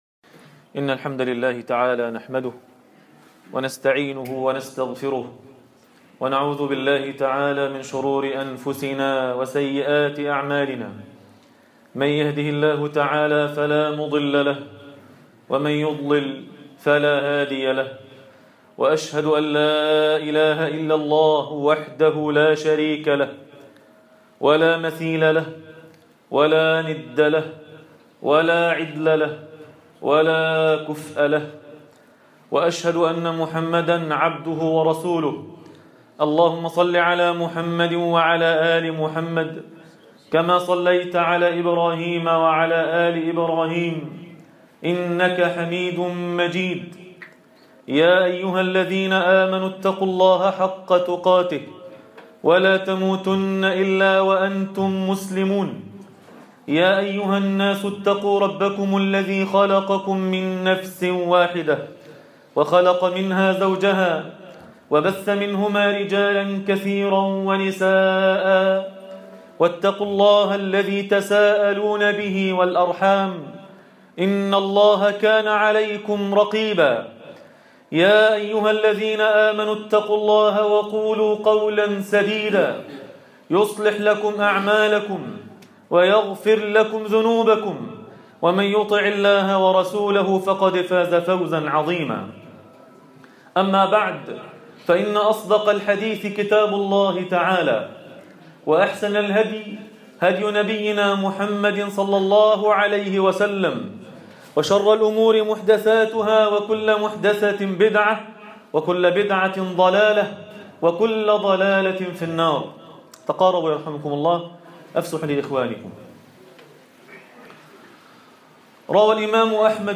تفاصيل المادة عنوان المادة إمارة السفهاء | خطبة جمعة تاريخ التحميل الخميس 9 اكتوبر 2025 مـ حجم المادة 26.56 ميجا بايت عدد الزيارات 96 زيارة عدد مرات الحفظ 48 مرة إستماع المادة حفظ المادة اضف تعليقك أرسل لصديق